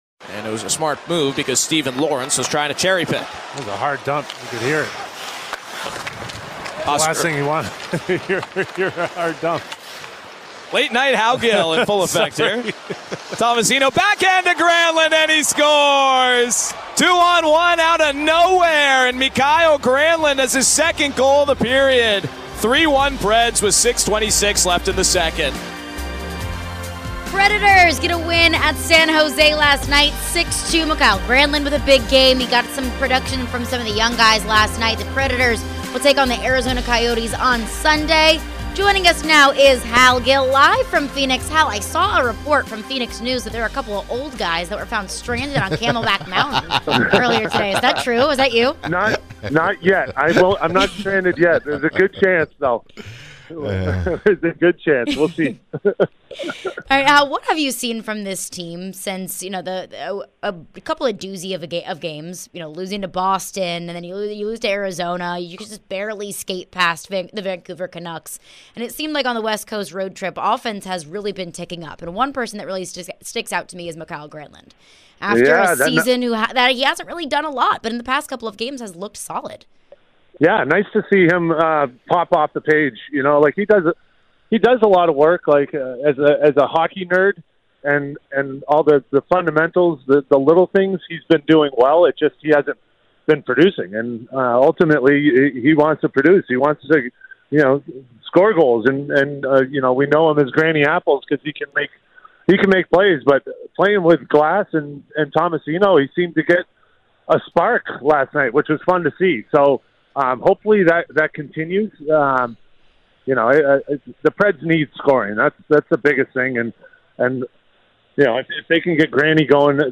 Hal Gill Interview (2-24-23)